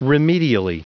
Prononciation du mot remedially en anglais (fichier audio)
Prononciation du mot : remedially